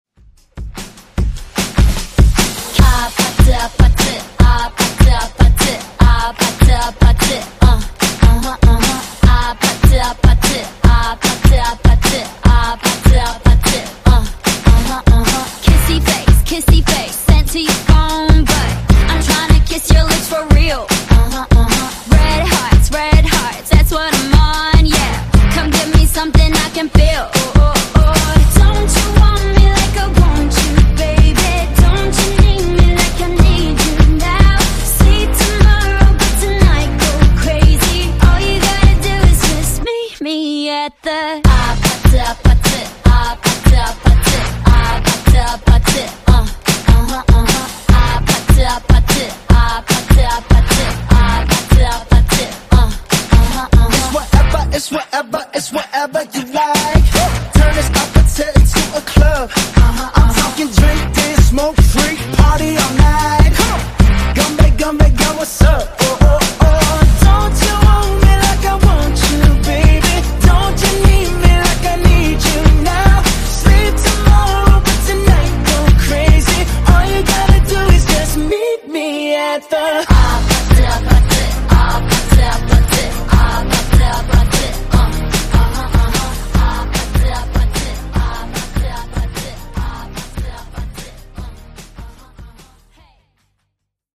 Genre: RE-DRUM
Clean BPM: 128 Time